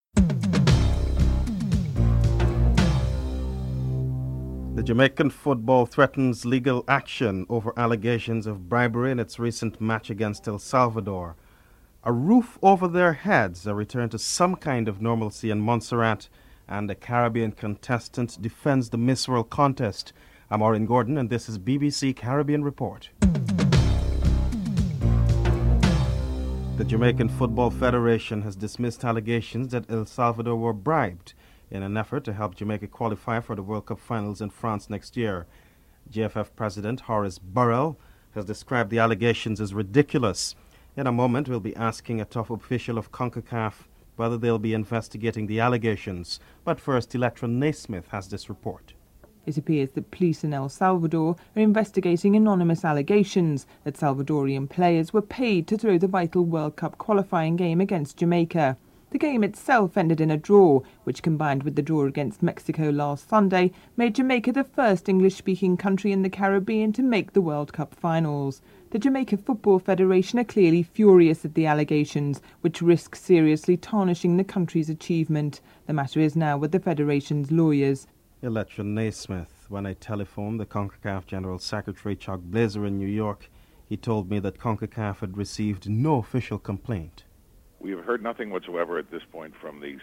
1. Headlines (00:00-00:25)
Minister of Tourism, Billie Miller is interviewed (10:26-13:57)